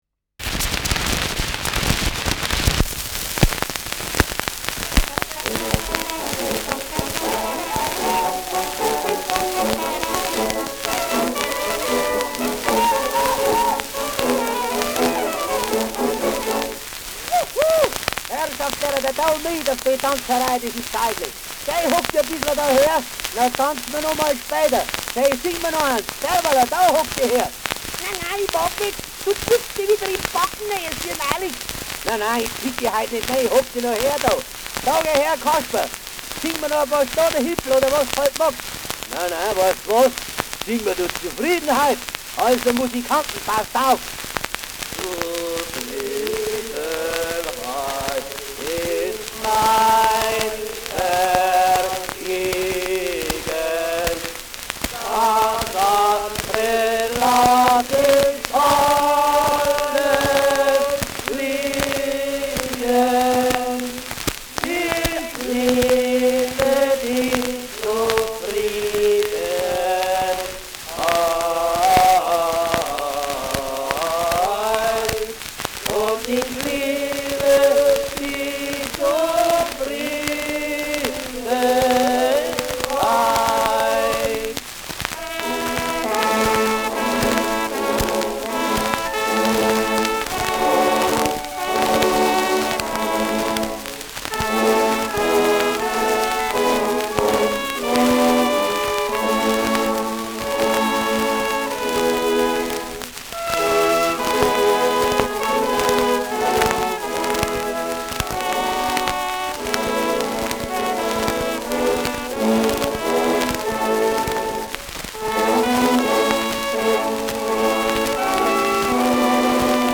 Schellackplatte
Nadelgeräusch : Durchgehend stärkeres Knacken
[Ansbach] (Aufnahmeort)
Humoristischer Vortrag* FVS-00003